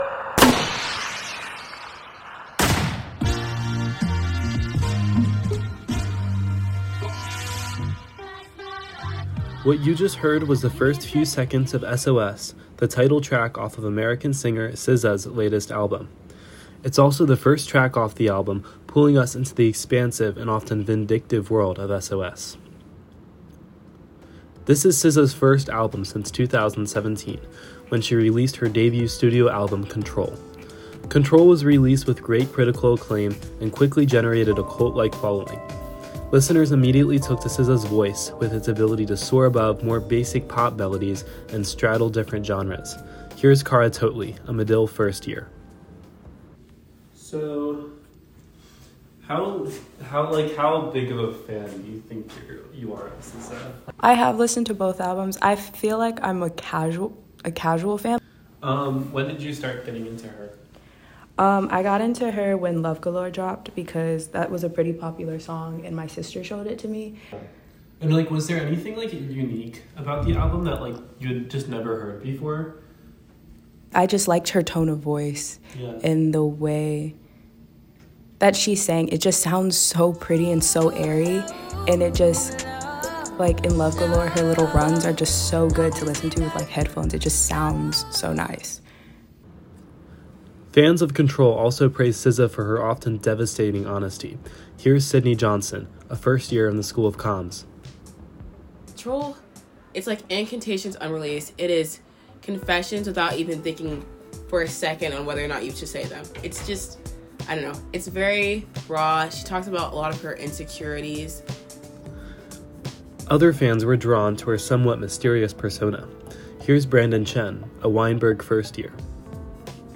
This Monday marked SZA’s 6th week on top the Billboard 200 chart for her second studio album SOS, With SZA coming to Chicago in February, students share some thoughts about her latest project.